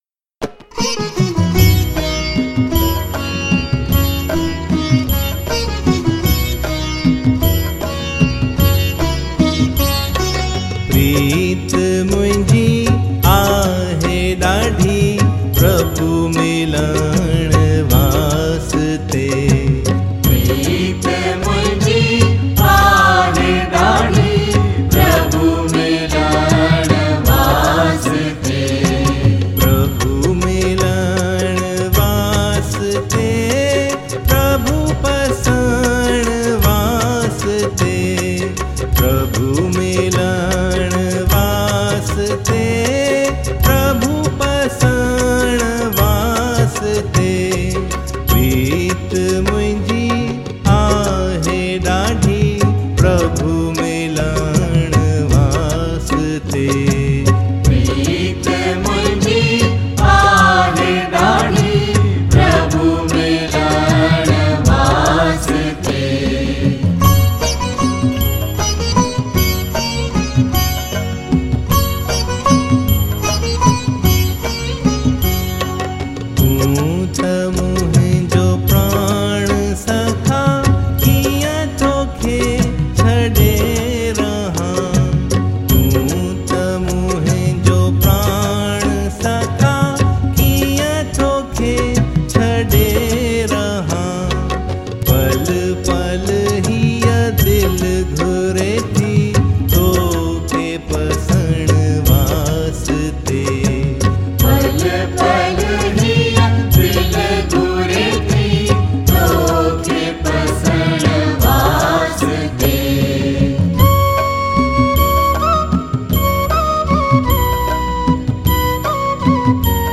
Hymns